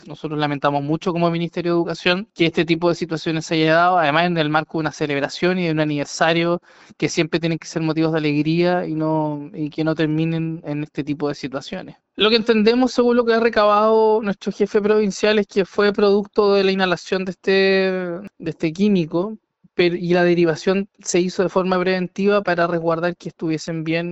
Consultado por La Radio, el seremi de Educación, Juan Pablo Gerter, lamentó lo ocurrido e indicó que los alumnos fueron llevados al recinto de salud de forma preventiva.
cu-mafil1-seremi.mp3